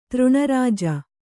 ♪ třṇa rāja